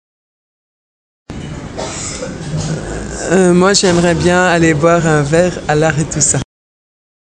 uitspraak Aretusa uitspraak Aretusa uitspraak Aretusa